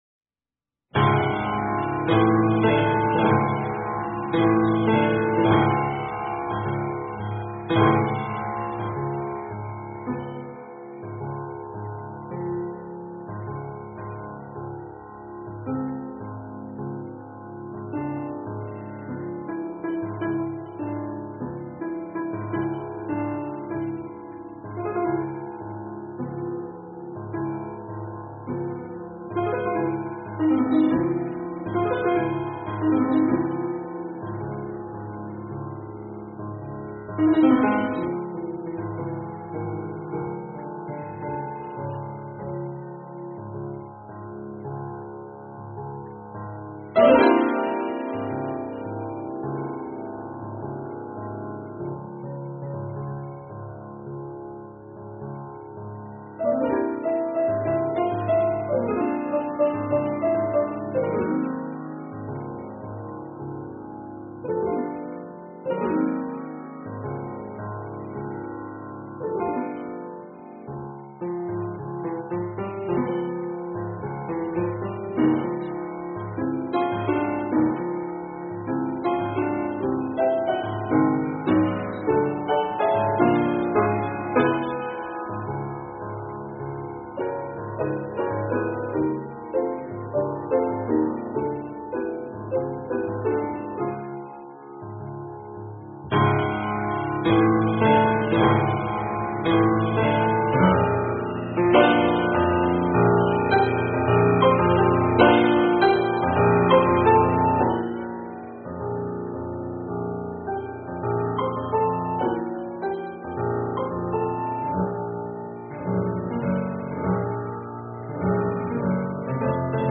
Фортепиано